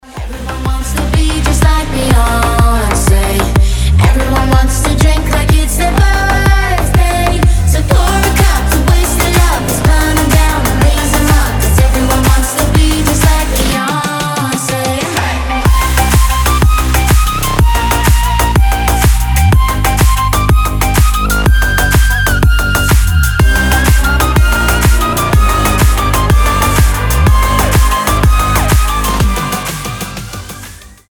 • Качество: 320, Stereo
громкие
зажигательные
EDM
Флейта
slap house